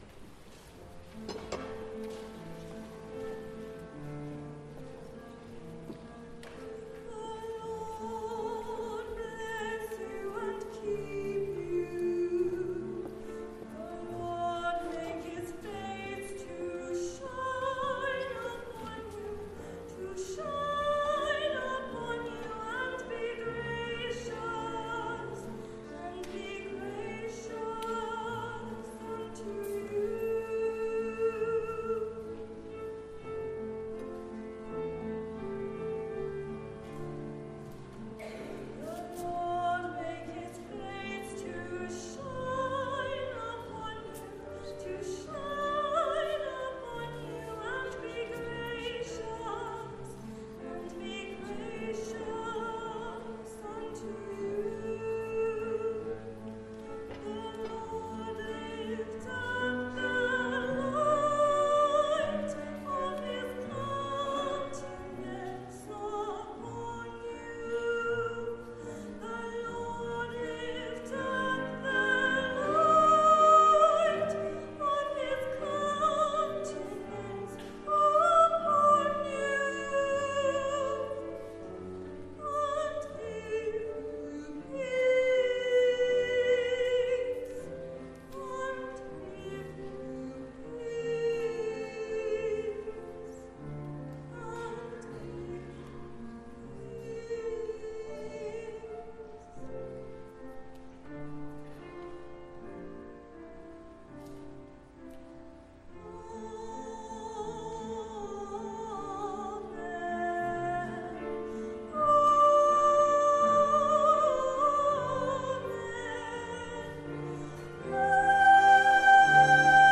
'A Celebration of Christmas 2017' - the Carol Service